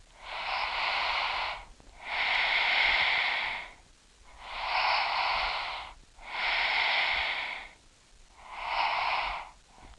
breathing.wav